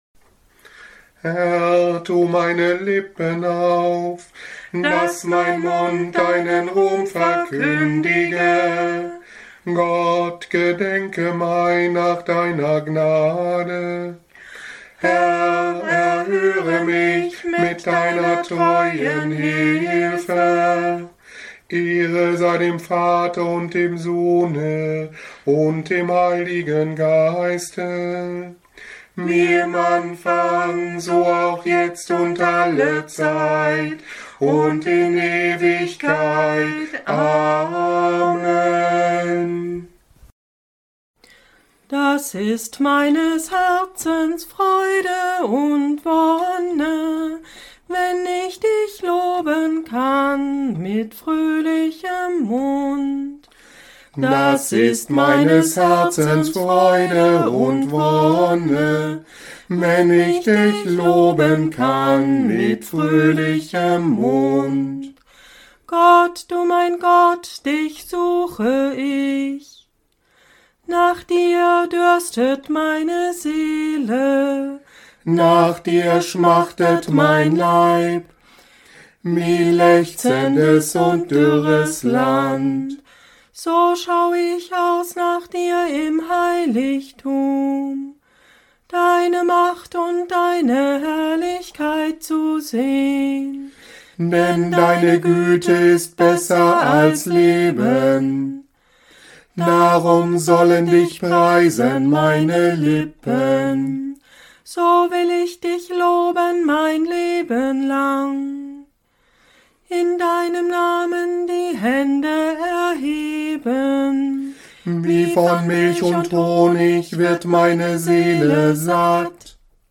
Morgengebet am Montag nach Okuli 2026 (9. März) Nummern im Tagzeitenbuch: 330, 501, 509, 515, 516, 517, Raum zum persönlichen Gebet, 28.1